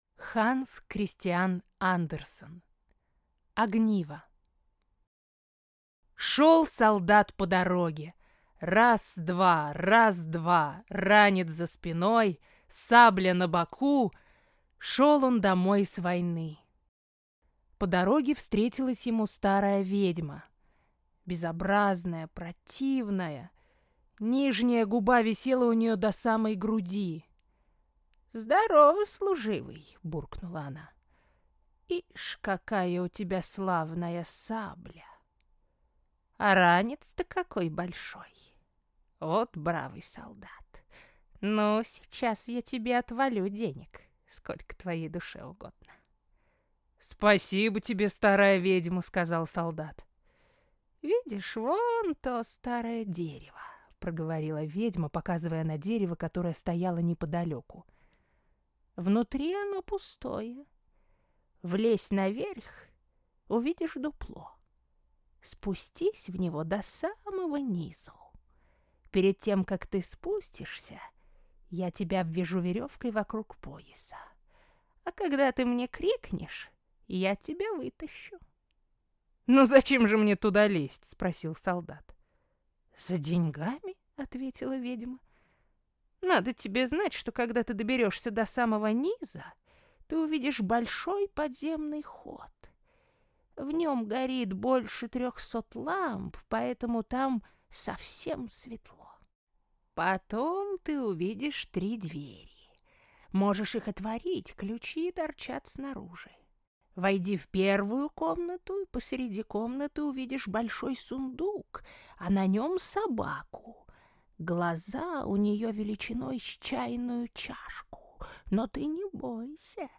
Аудиокнига Огниво | Библиотека аудиокниг